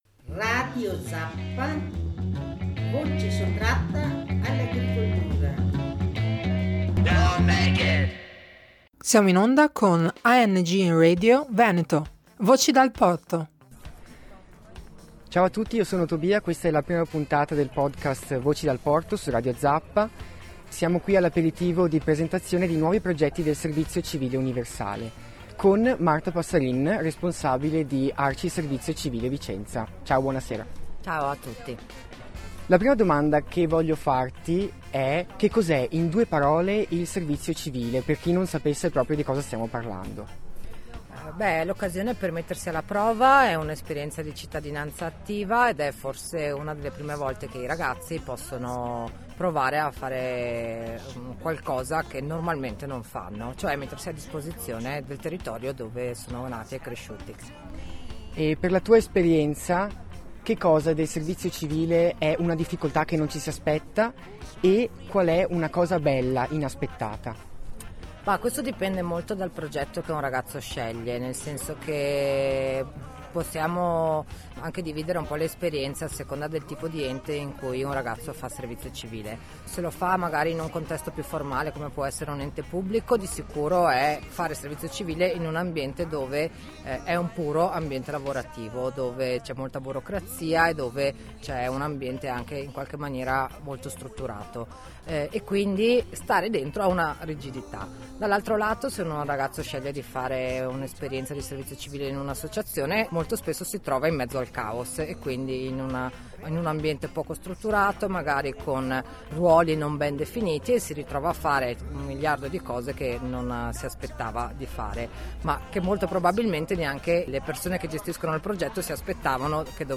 Il Servizio Civile a Vicenza passa per l’aperitivo. Cosa è cambiato in questi anni, come scegliere il proprio progetto, come sfruttare al meglio quest’opportunità di crescita: ne abbiamo parlato all’InfoSpritz di Porto Burci.